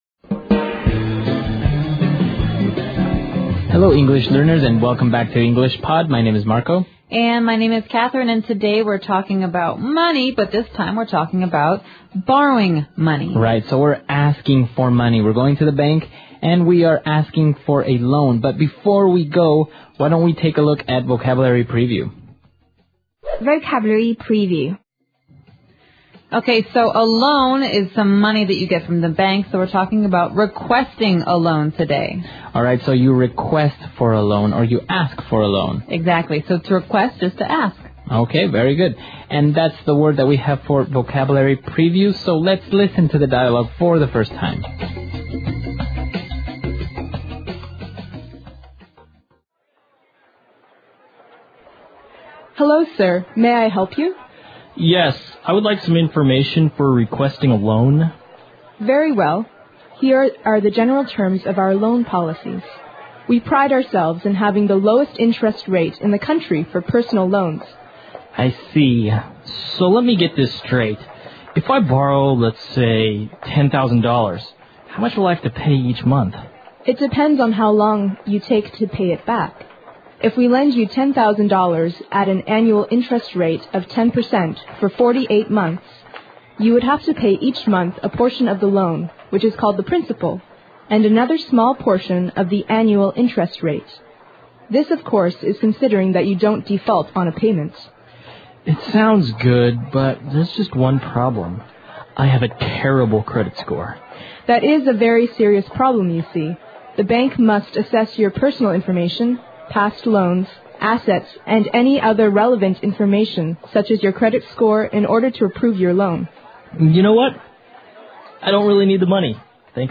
纯正地道美语(外教讲解)162：申请贷款 听力文件下载—在线英语听力室